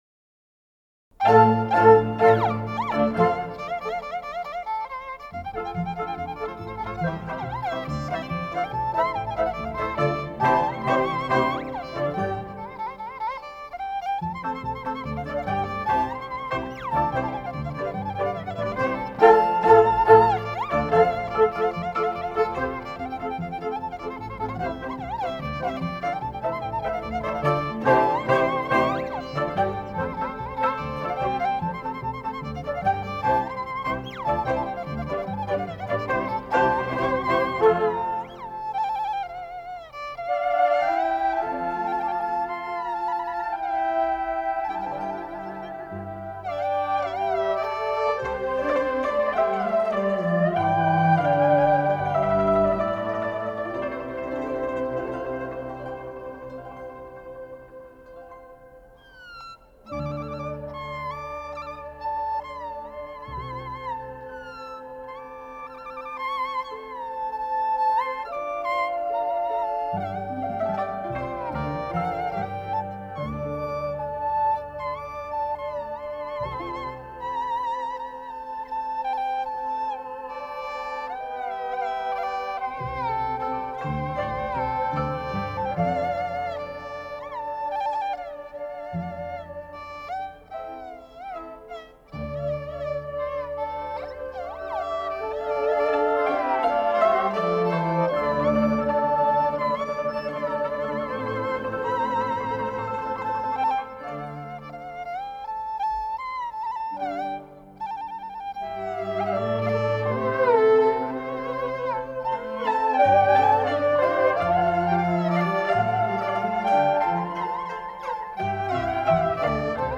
22-原野-山东小曲-板胡演奏.mp3